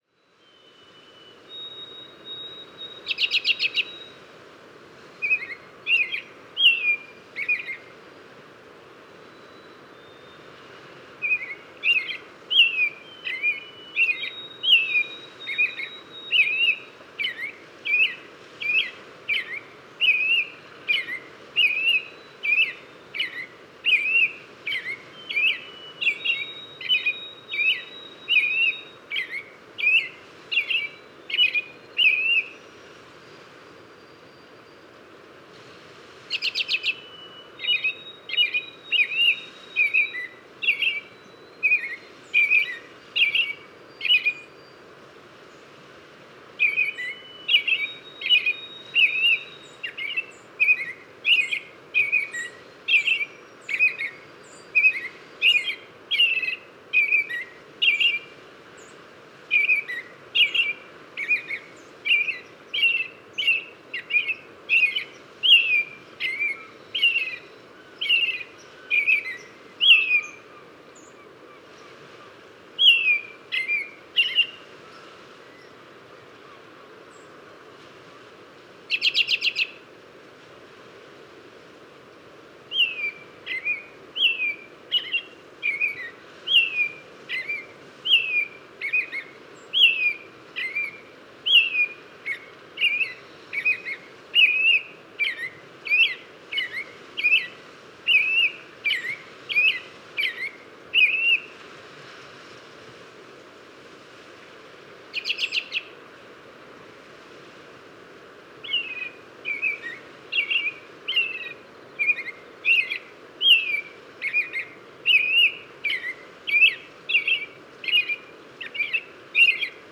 American Robin – Turdus migratorius
A solitary American Robin sings perched in a tree while a distant train resonates in a dull spring sky. Species : American Robin, American Crow, Canada Goose, Red-winged Blackbird, Song Sparrow, Ring-billed Gull.